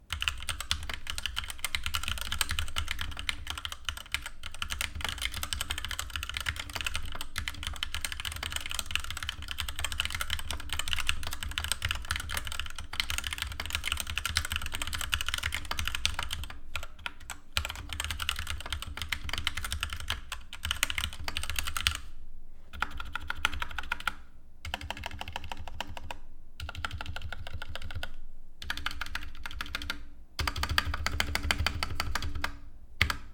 Brzmienie
Co prawda klawisze stabilizowane są cichutkie i nie szeleszczą za sprawą użycia genialnej jakości stabilizatorów, ale cała reszta klawiatury nie uwiodła mnie swoim brzmieniem.
Suma dodania tych dwóch cech jest prosta – klawiatura brzmi tanio, plastikowo i słychać wydobywające się z niej metaliczne dźwięki.
Tu natomiast mamy nieciekawy dla uszu wysoki dźwięk, wydobywający się za każdym stuknięciem w akompaniamencie rezonujących sprężyn.
Wspomnę jeszcze jednak, że na szczęście nie jest to klawiatura przesadnie głośna, poniekąd dzięki obudowanym przełącznikom.
Tak brzmi klawiatura Genesis Thor 660.
recenzja-Genesis-Thor-660-White-soundtest.mp3